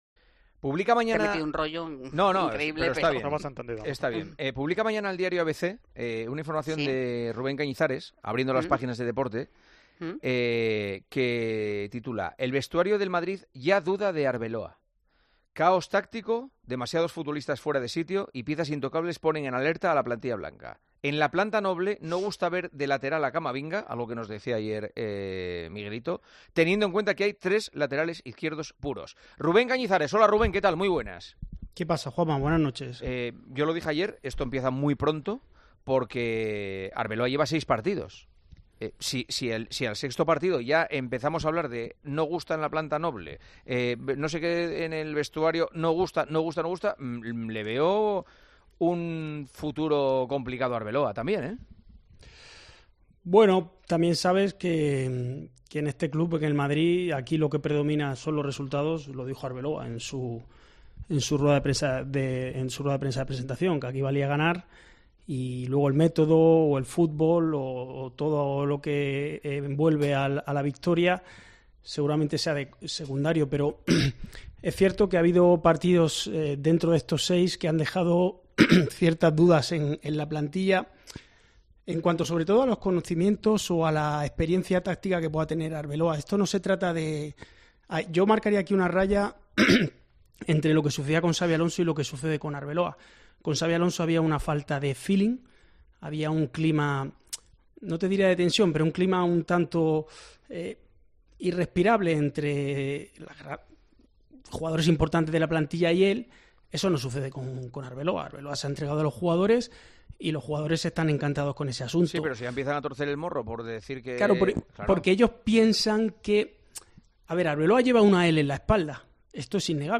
Juanma Castaño y los tertulianos de El Partidazo de COPE debaten sobre las dudas del vestuario del Real Madrid sobre Arbeloa